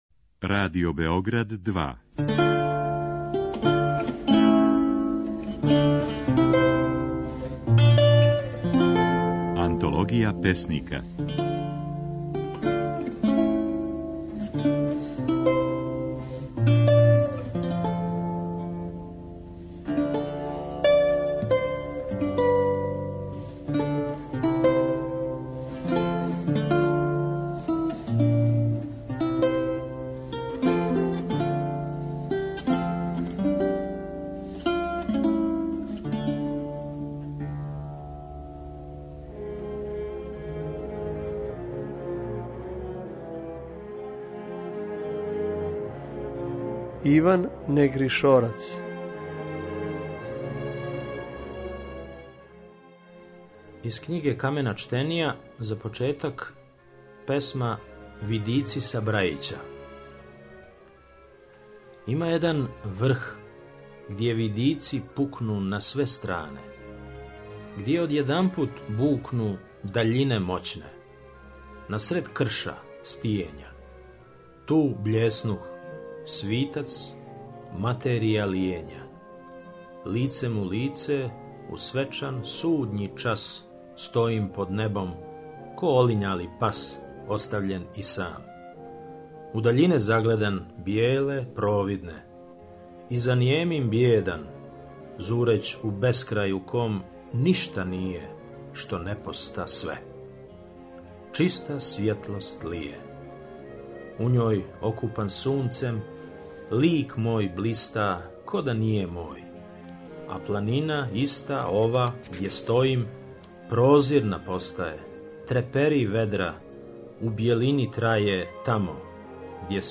Емитујемо снимке на којима своје стихове говоре наши познати песници